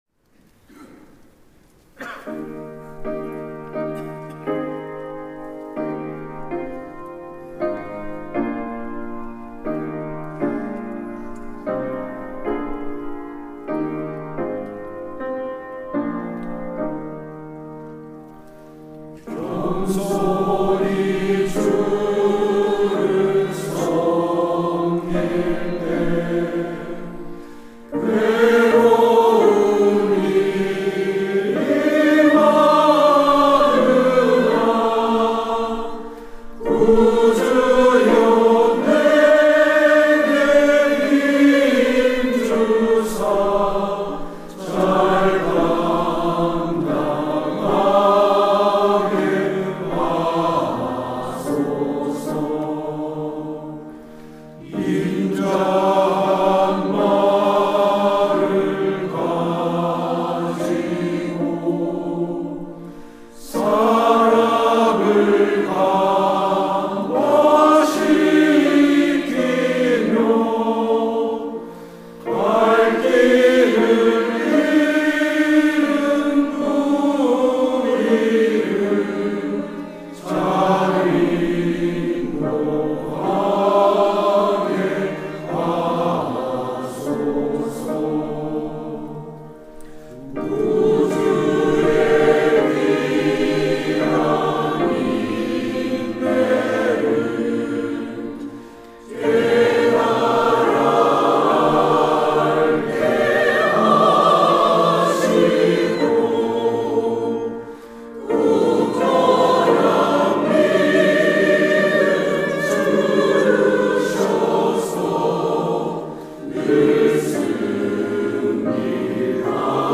특송과 특주 - 겸손히 주를 섬길 때
항존직분자